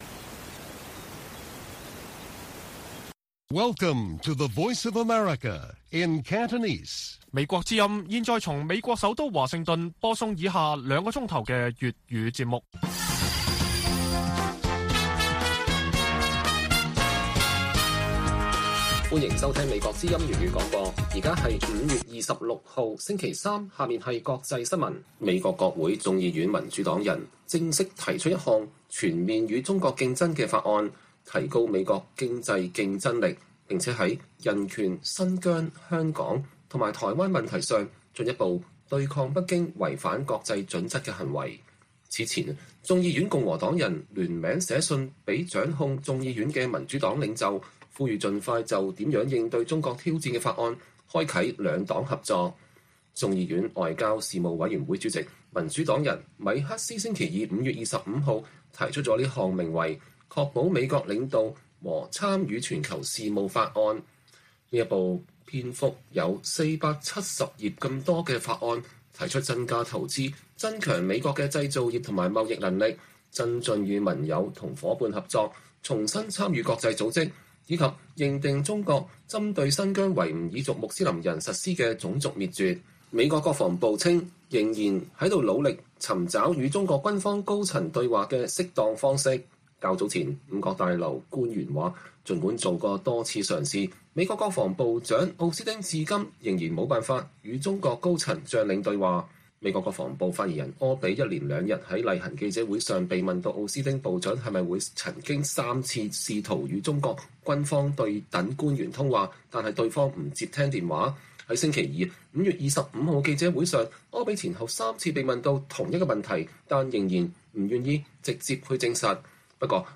粵語新聞 晚上9-10點: 美國眾議院民主黨領袖提出法案全面反制中國挑戰